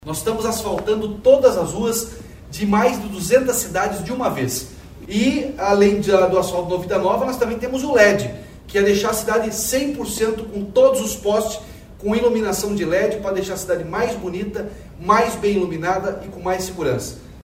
Sonora do governador Ratinho Junior sobre as liberações desta terça pelo Asfalto Novo, Vida Nova